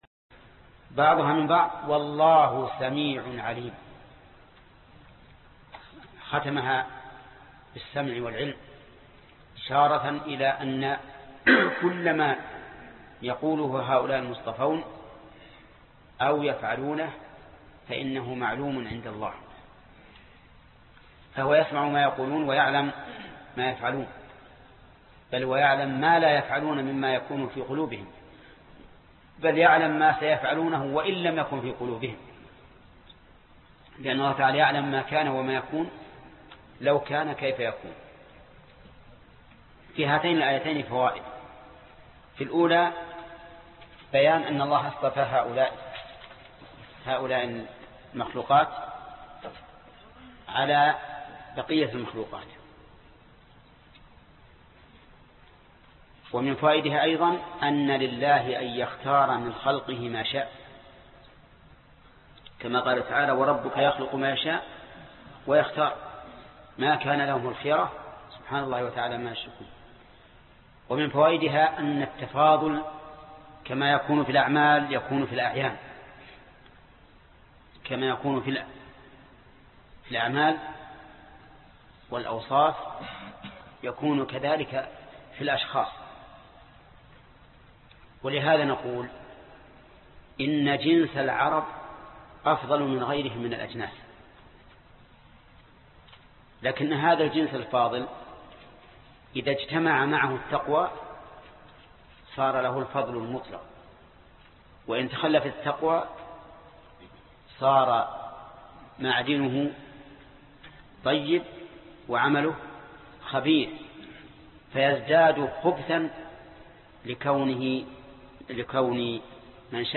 الدرس 66 فوائد الآية 34،33 (تفسير سورة آل عمران) - فضيلة الشيخ محمد بن صالح العثيمين رحمه الله